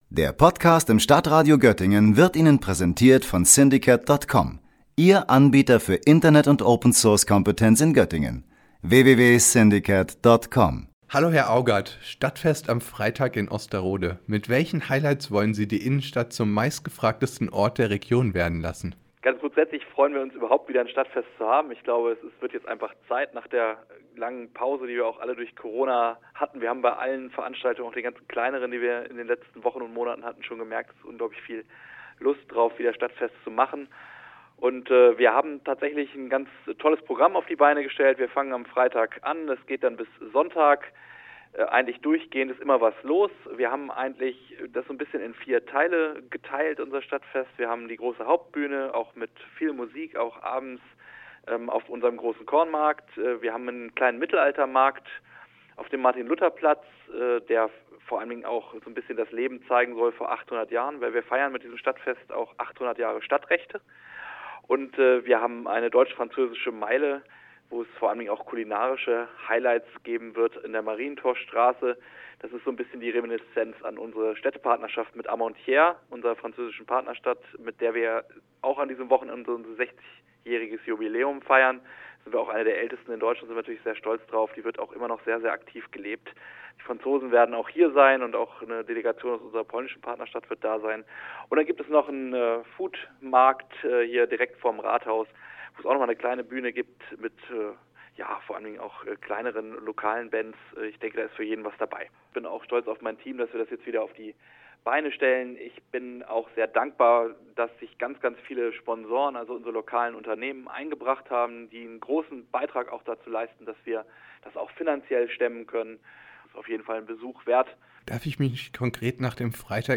Stadtfest in Osterode - Bürgermeister Jens Augat im Interview